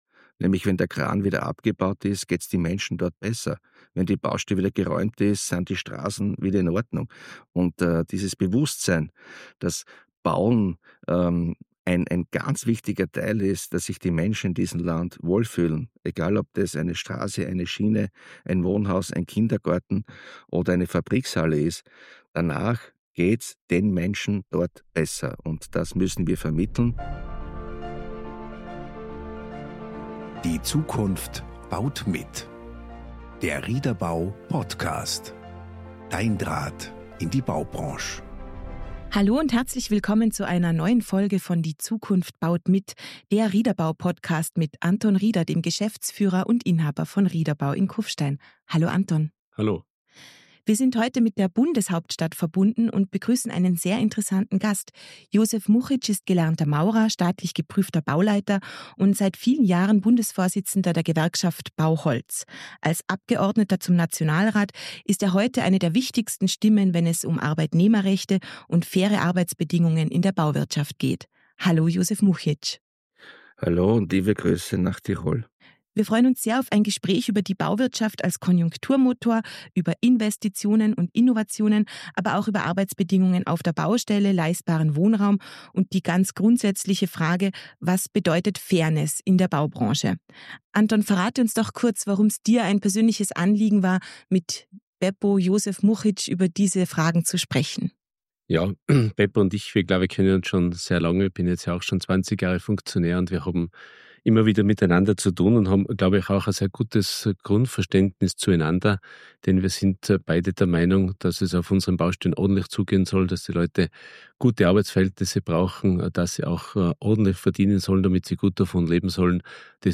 Ein aufrüttelndes Gespräch über Werte, Wirtschaft und Wandel – und darüber, wie die Bauwirtschaft Verantwortung übernehmen kann, um Stabilität und Perspektive zu schaffen.